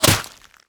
bullet_impact_ice_09.wav